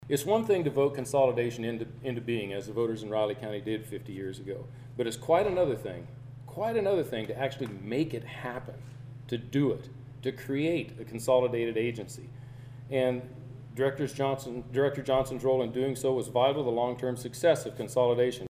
The Riley County Police Department held a 50th anniversary kickoff event Thursday at its headquarters.
Former RCPD Director Brad Schoen also spoke on why consolidation works, honoring former director Alvan Johnson.